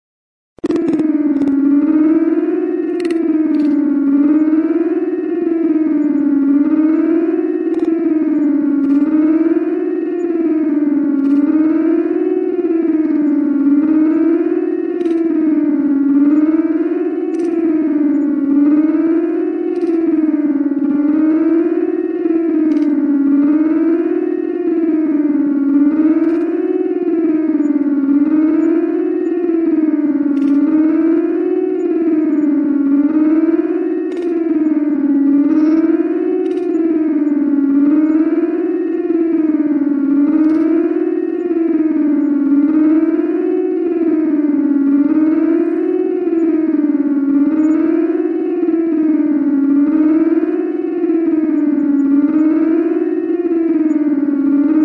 Sound Effects
Night Siren